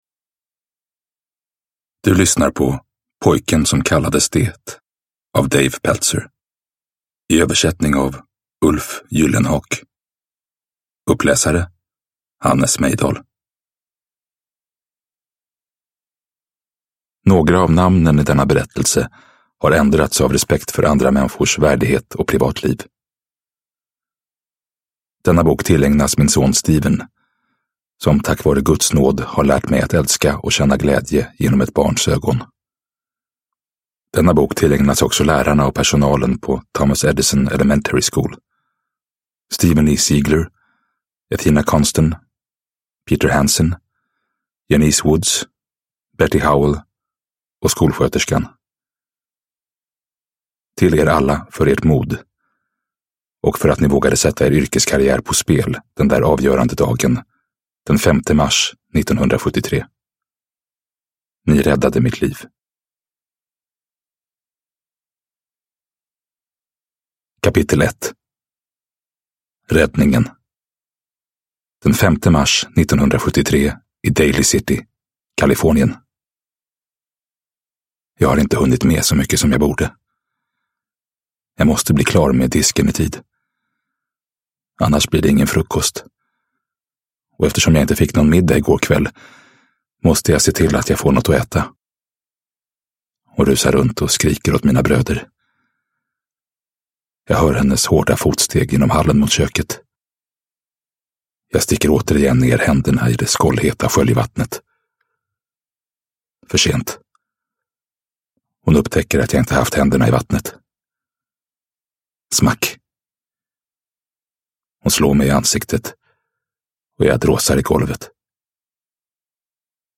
Pojken som kallades det – Ljudbok – Laddas ner